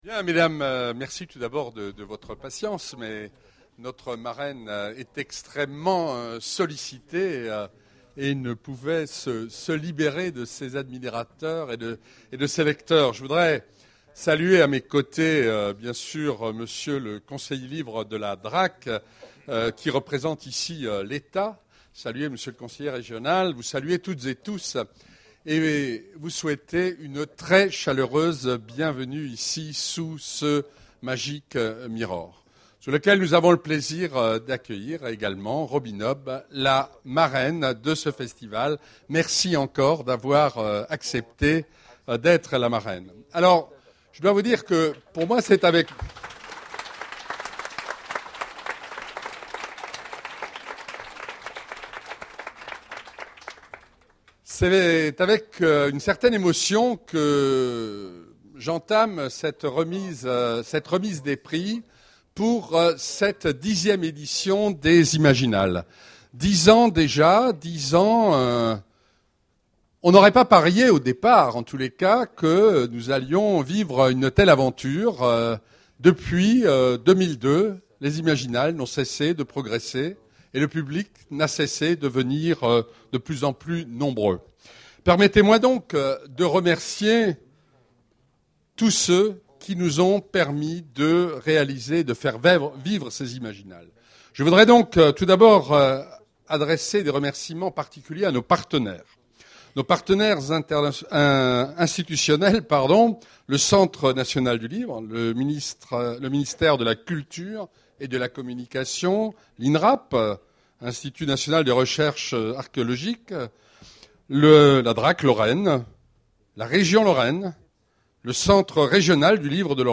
Voici l'enregistrement de la remise des Prix Imaginales 2011.